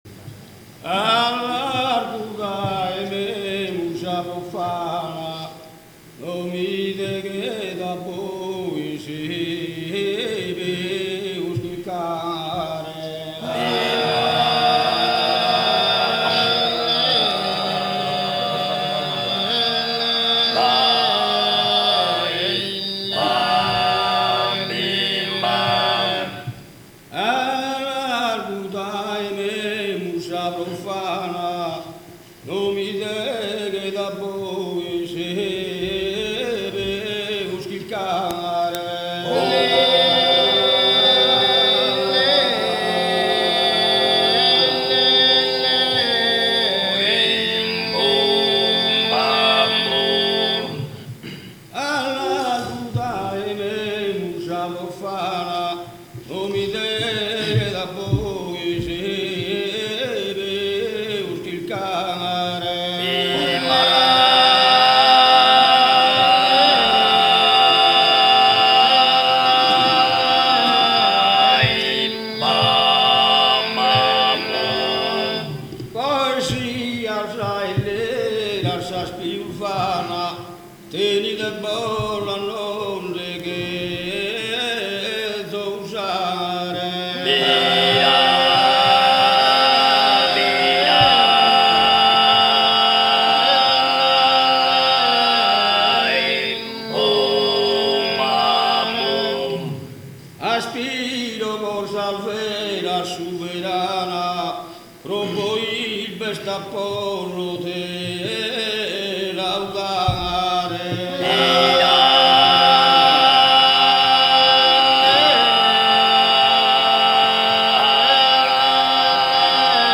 Il coro di Pattada Una esecuzione del coro con una nuova formazione
Il brano vocale del coro